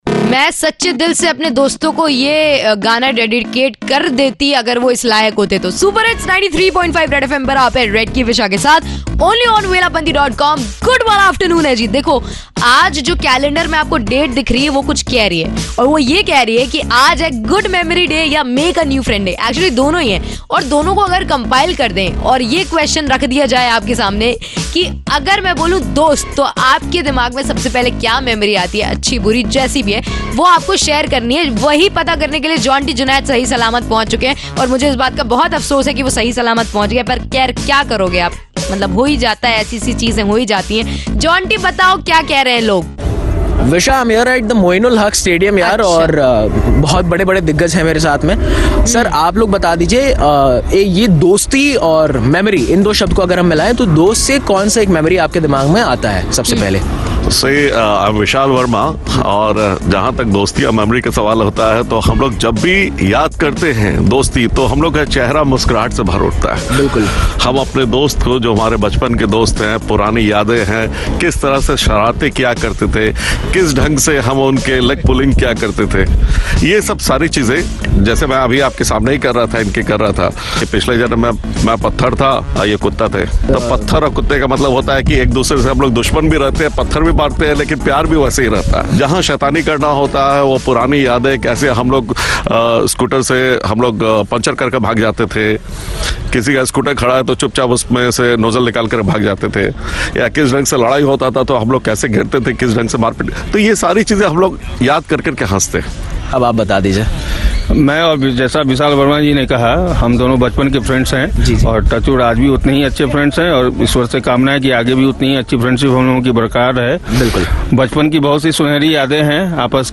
Vox Pop